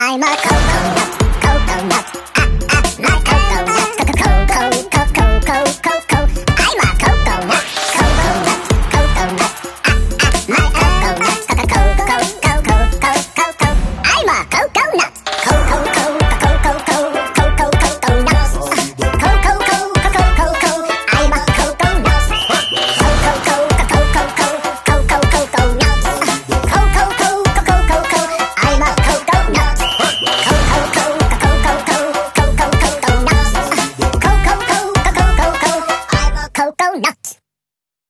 Stefan Löfven ( Prime Minister 🇸🇪 ) Sings